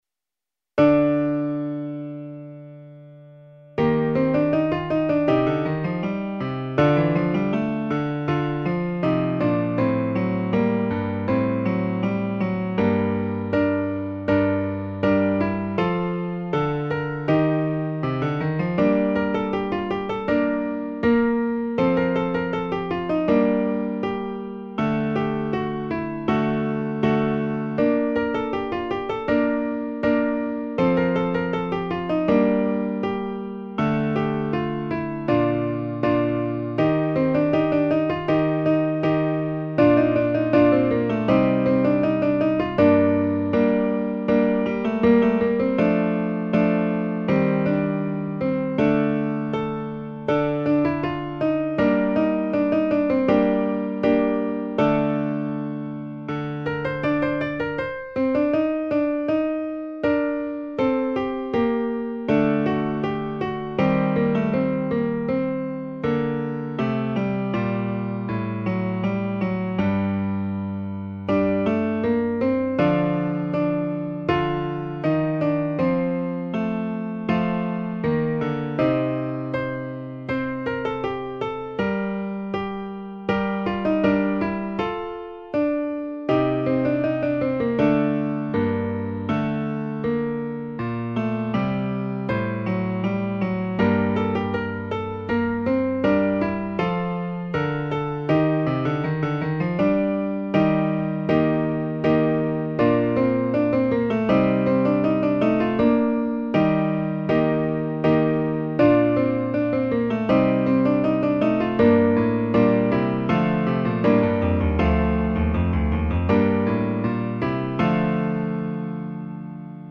Instrumental en Re m con